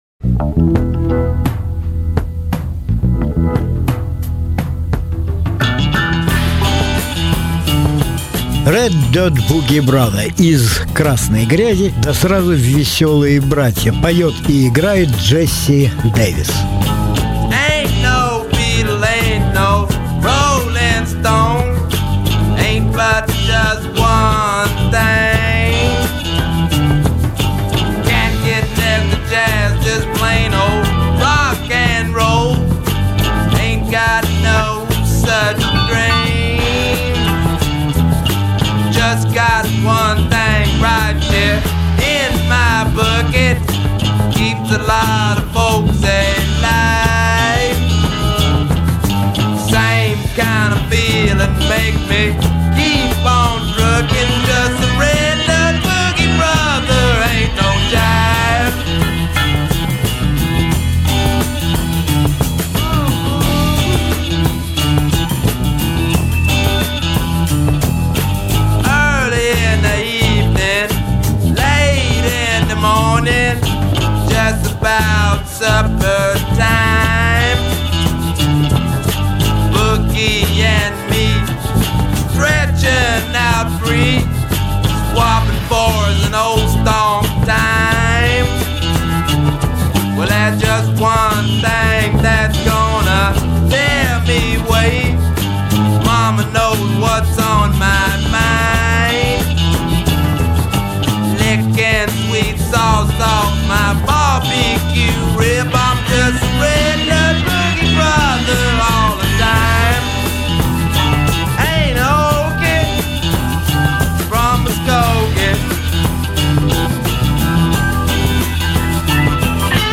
Различные альбомы Жанр: Блюз СОДЕРЖАНИЕ 23.09.2019 1.